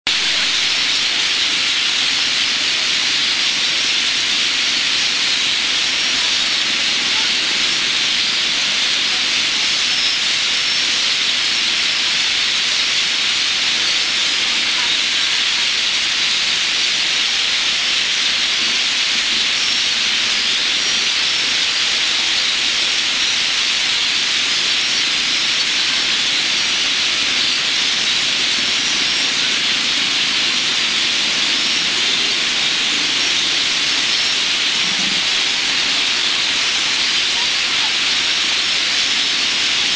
Asian Glossy Starling
10000s on roost -what do
Aplonis panayensis
you expect? Noisy!!